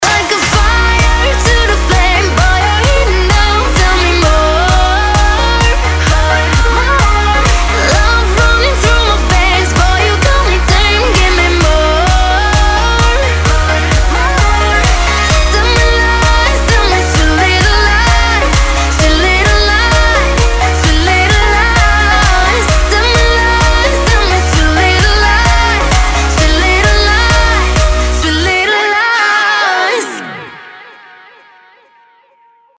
• Качество: 320, Stereo
танцевальный сингл